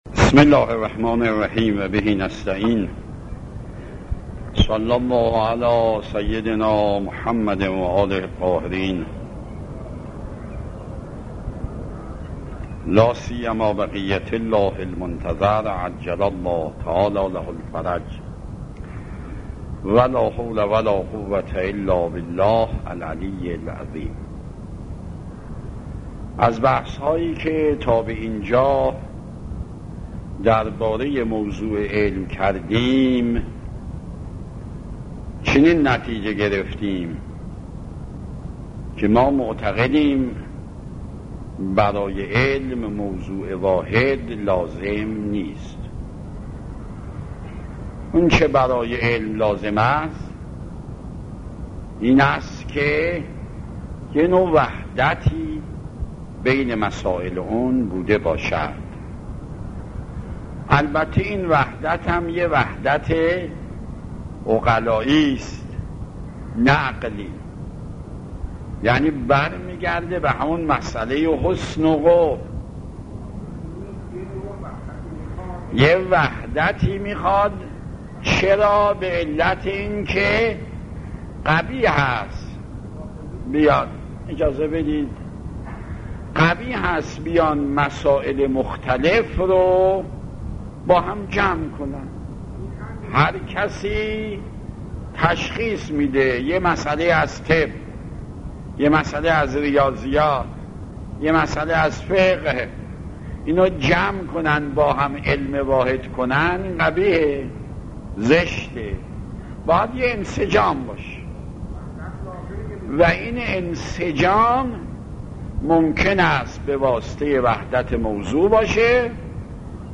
آيت الله مکارم شيرازي - خارج اصول | مرجع دانلود دروس صوتی حوزه علمیه دفتر تبلیغات اسلامی قم- بیان